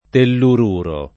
[ tellur 2 ro ]